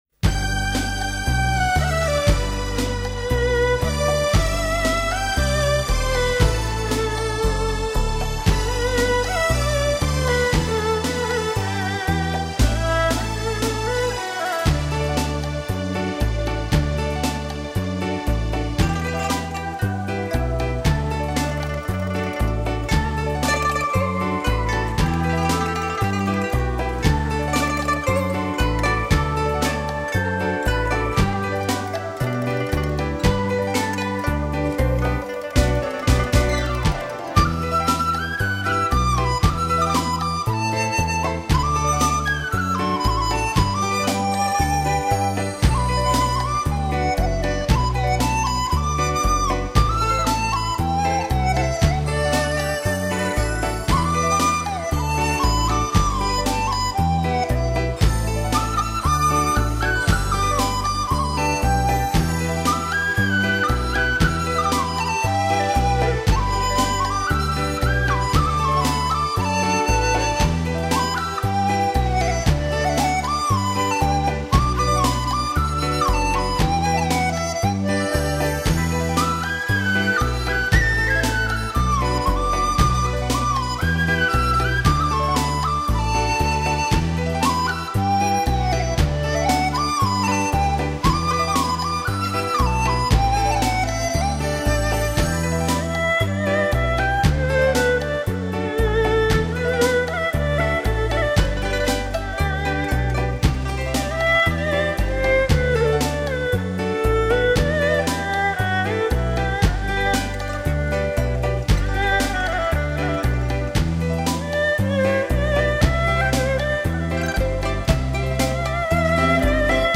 音乐类型：舞曲节奏　　　　　　　　．
美妙的旋律  轻盈的舞步